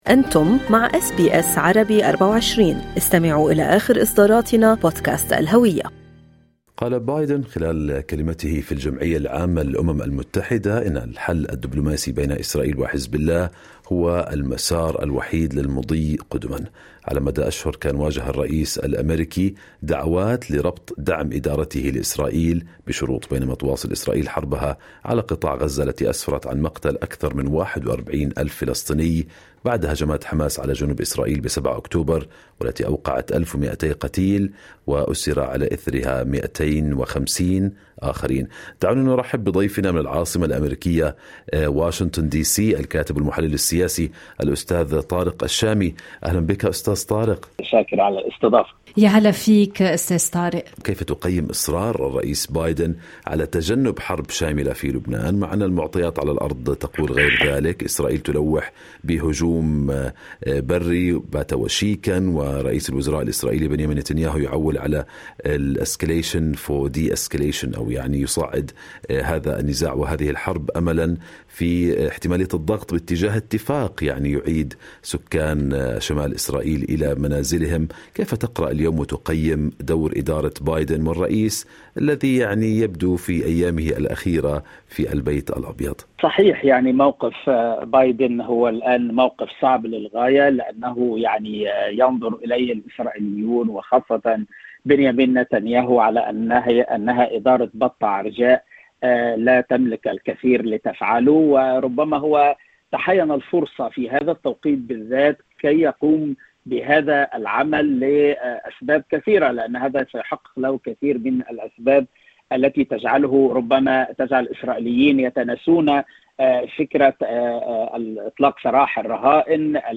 نحلل الموقف الأمريكي مع ضيفنا من واشنطن الكاتب والمحلل السياسي